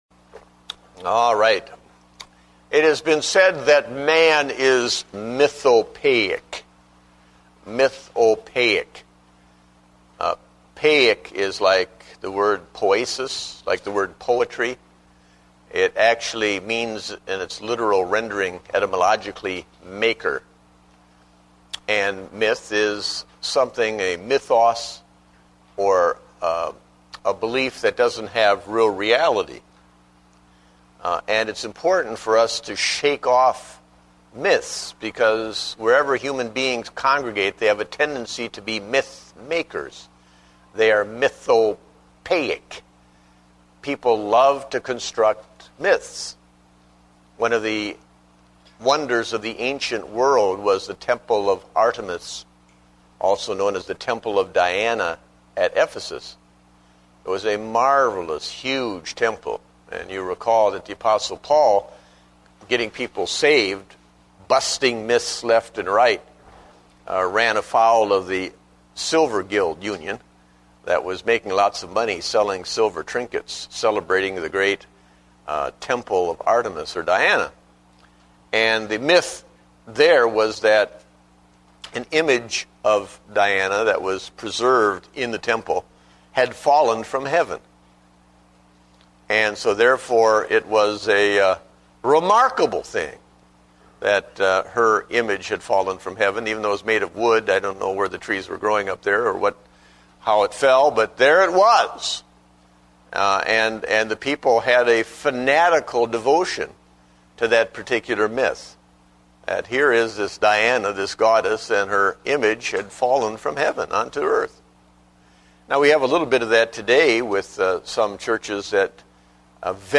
Date: September 13, 2009 (Adult Sunday School)